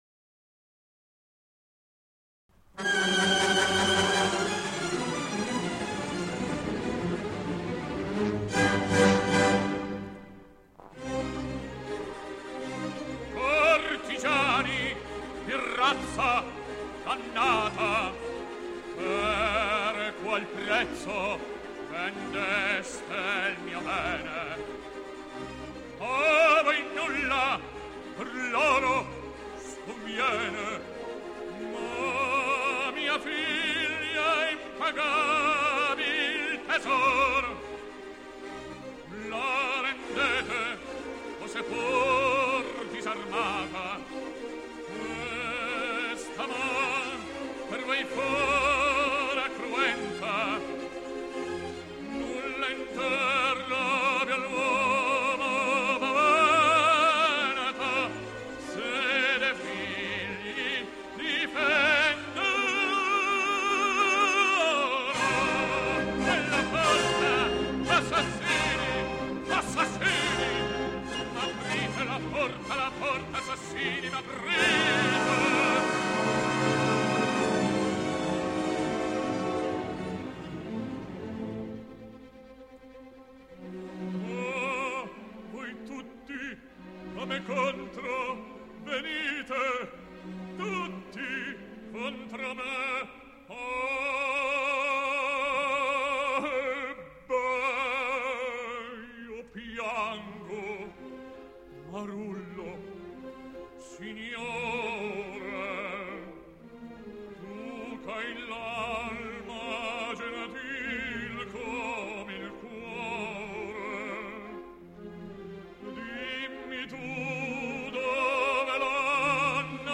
baritono